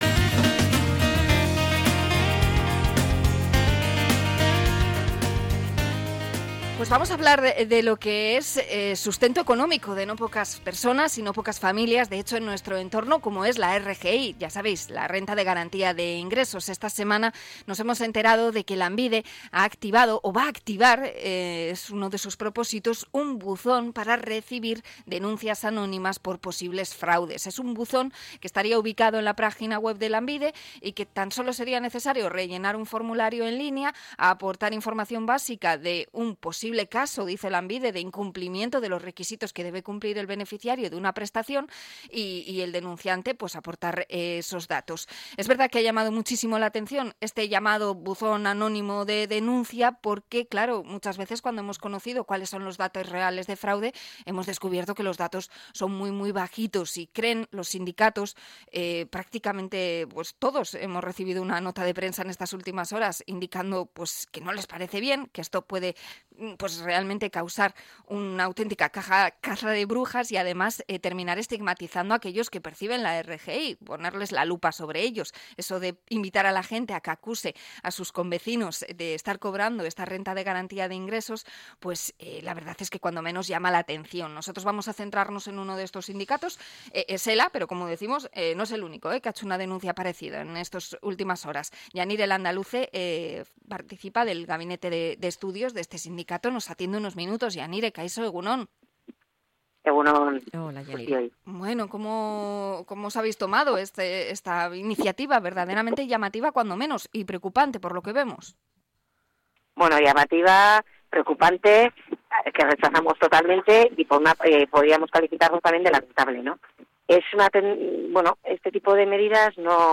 Entrevista a sindicato ELA por el buzón de denuncia de fraude de Lanbide